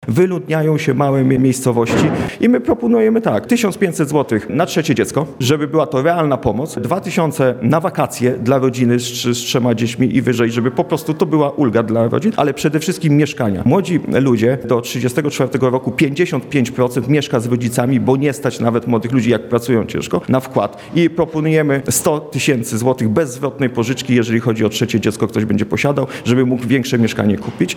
Ogólnopolska Federacja Bezpartyjni Samorządowcy przedstawiła swój program podczas kongresu w Kąkolewnicy w powiecie radzyńskim.